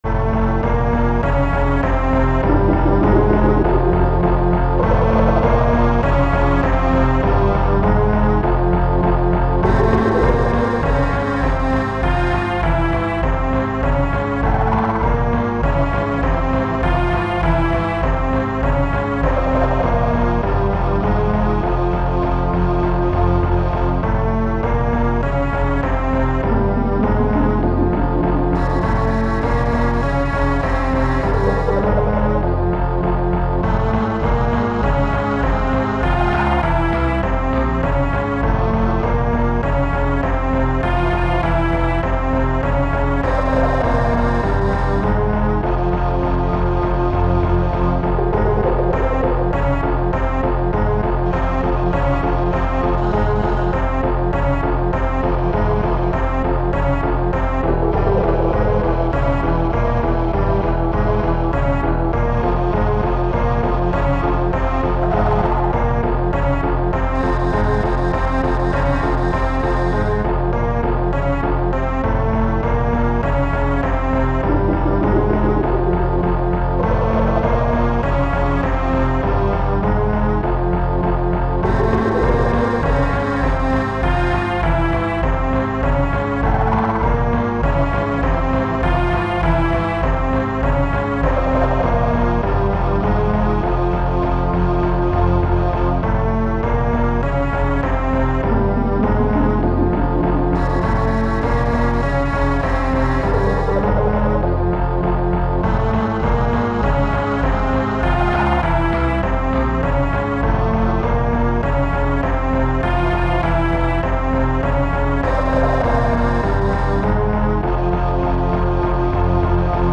High-quality mp3s that are identical to the game's music.